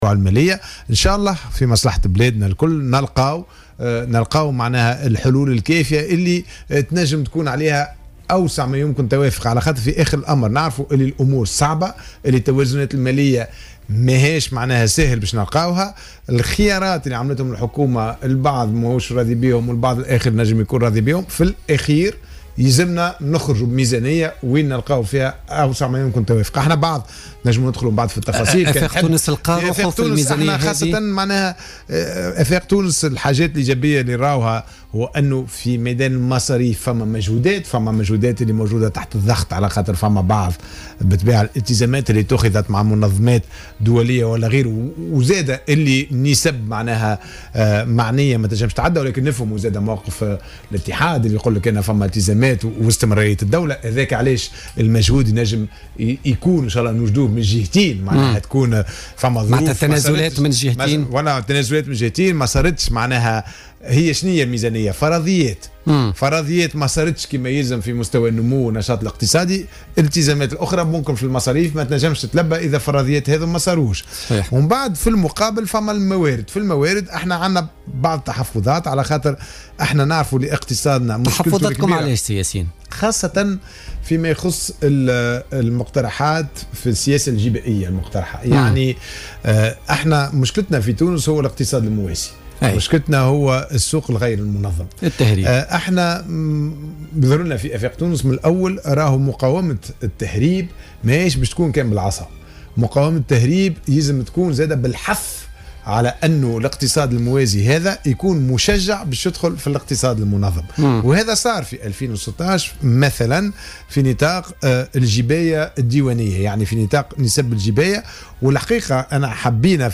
أكد رئيس حزب أفاق تونس ياسين ابراهيم ضيف بوليتيكا اليوم الخميس 3 نوفمبر 2016 أن التوافق حول مشروع قانون المالية لسنة 2017 يقتضي تقديم تنازلات من جميع الاطراف السياسية منها والإجتماعية على غرار اتحاد الشغل على حد قوله.